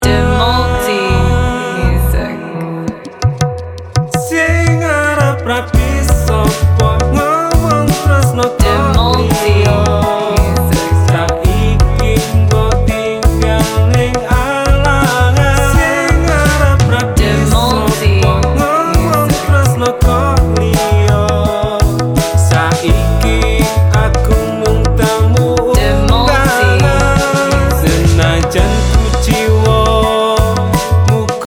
Suasana jadi galau, tapi meriah.